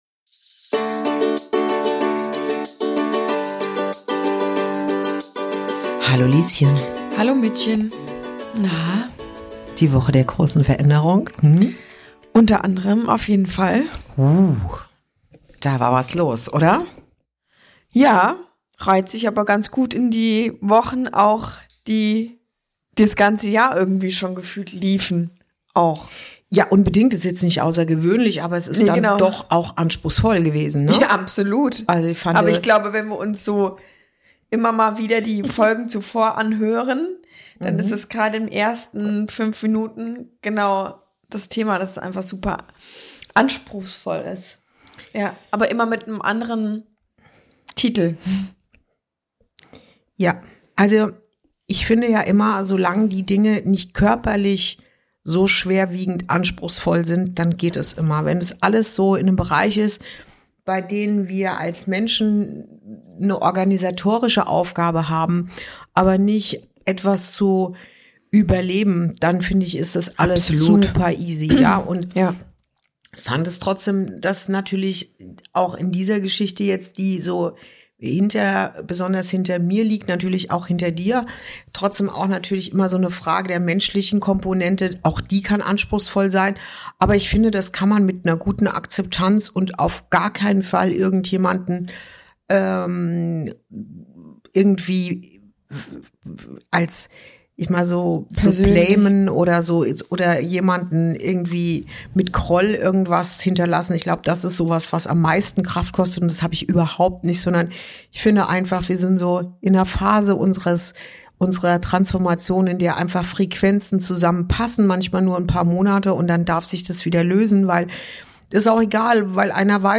Folge 25: Ein neuer Blick auf dich – über Berufung, Körpergefühl & große Veränderungen ~ Inside Out - Ein Gespräch zwischen Mutter und Tochter Podcast